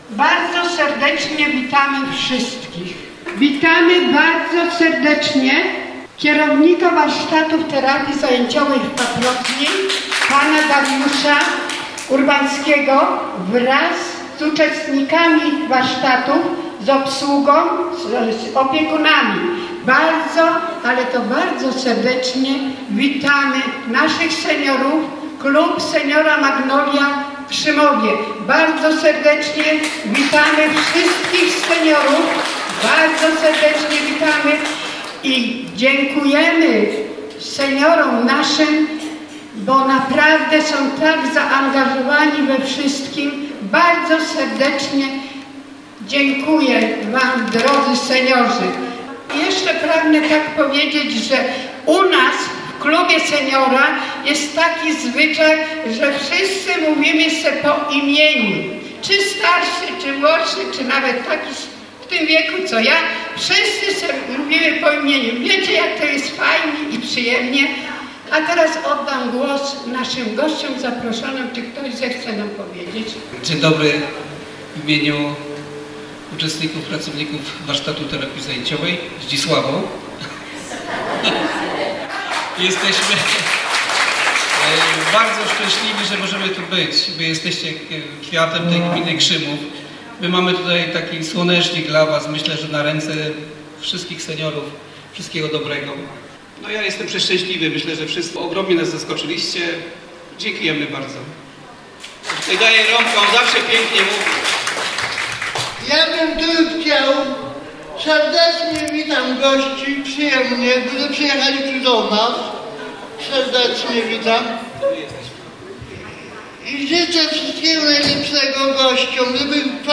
Spotkanie zorganizowane dwudziestego dziewiątego sierpnia w świetlicy wiejskiej w Paprotni było swego rodzaju rewanżem.
Spotkanie szybko przerodziło się w biesiadę pełną smaków, muzyki i tańca